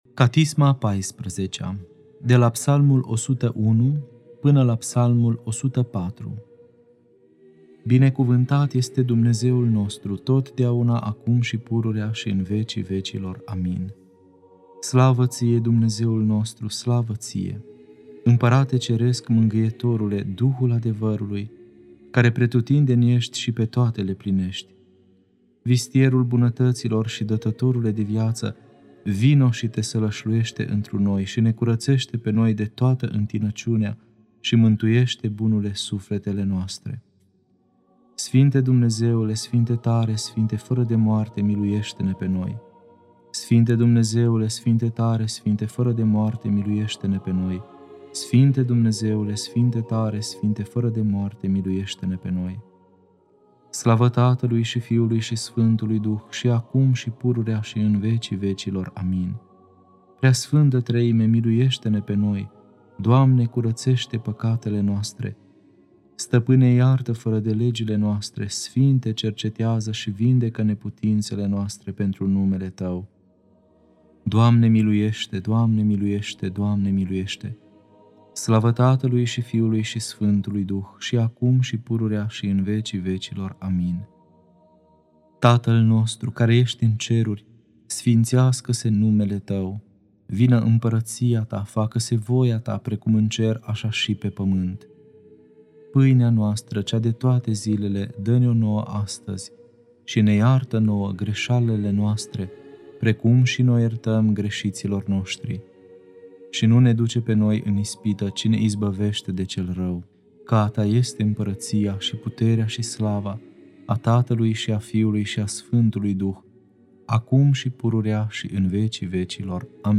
Catisma a XIV-a (Psalmii 101-104) Lectura